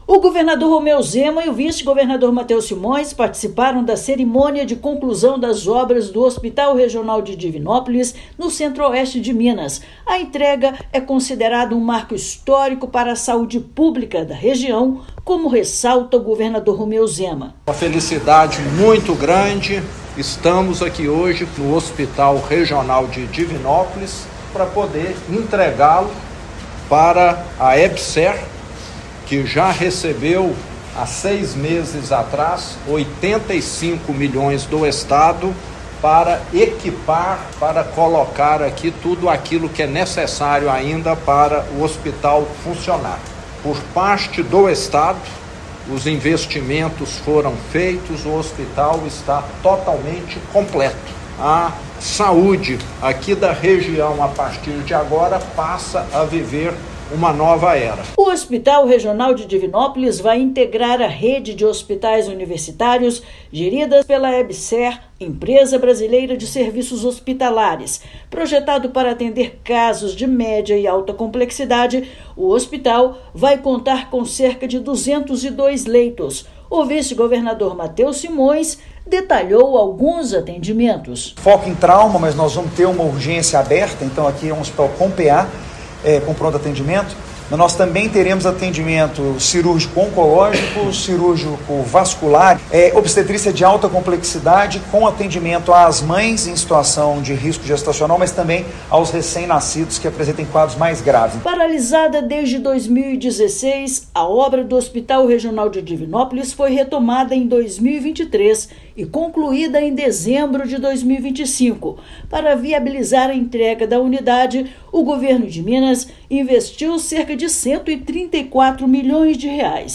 Com investimento de R$ 134 milhões do Estado, complexo amplia acesso a serviços de média e alta complexidade no Centro-Oeste mineiro. Ouça matéria de rádio.